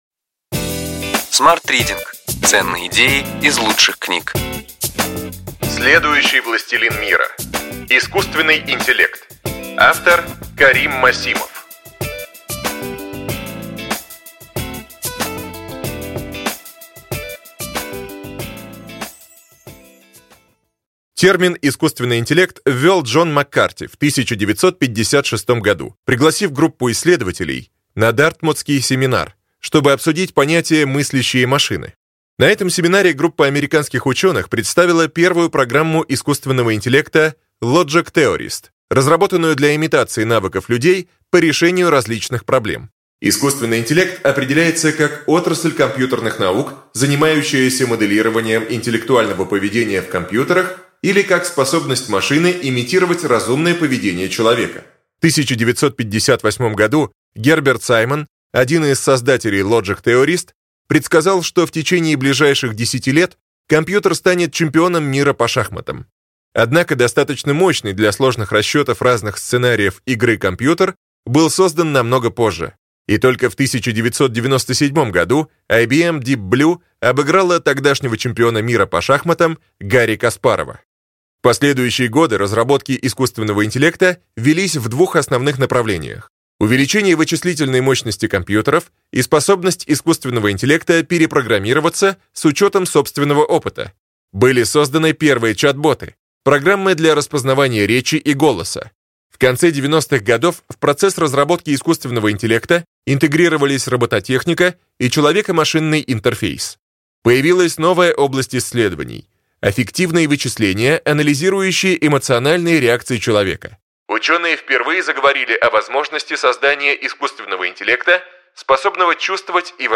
Аудиокнига Ключевые идеи книги: Следующий властелин мира. Искусственный интеллект.